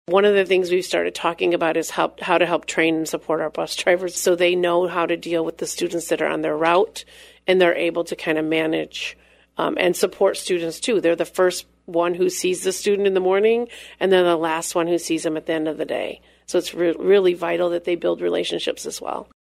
“One of the things that we started talking about is how to train and support our bus drivers,” she said on “WHTC Talk of the Town” during a Tuesday (July 20, 2021) appearance, “so they know how to deal with the students on their route. They’re the first to see the student in the morning, and then the last one to see them at the end of the day, so it’s really vital that they build relationships as well.”